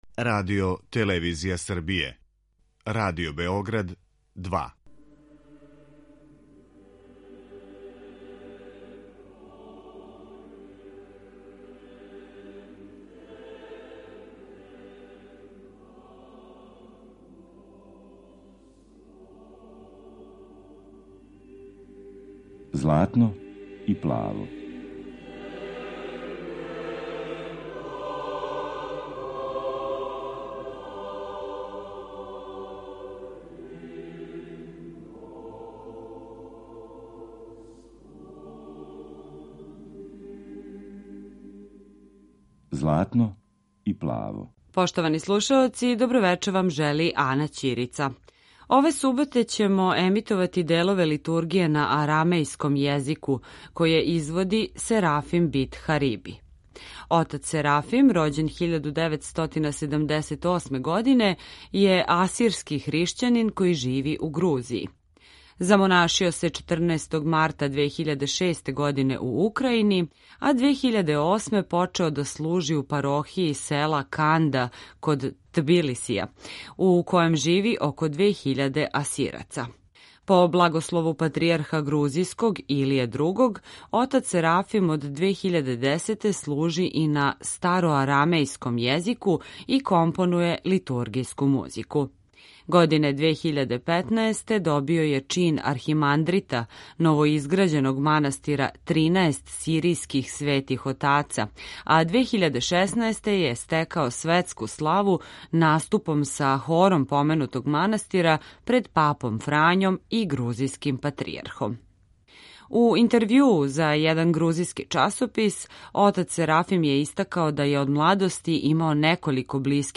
Одломци Литургије на арамејском језику
Емисија посвећена православној духовној музици.